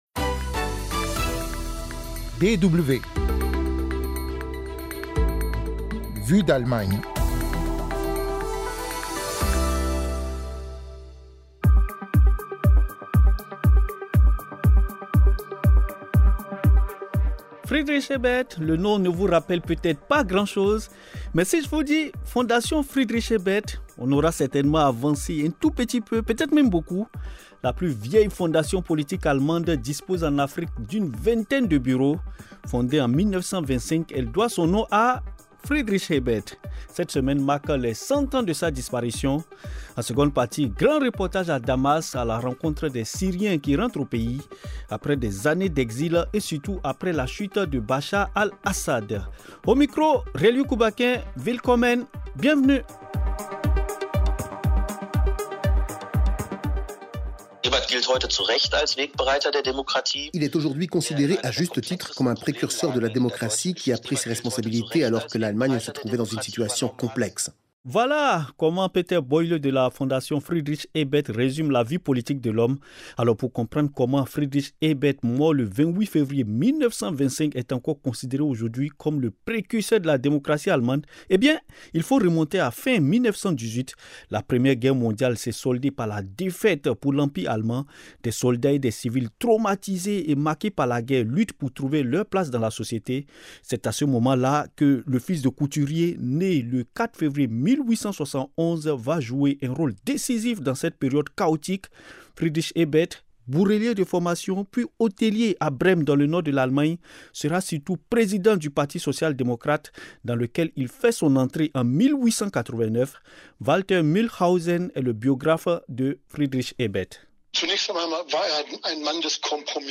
Vu d'Allemagne est un podcast hebdomadaire sur l'Allemagne, chaque mercredi, avec un grand reportage international en seconde partie d'émission.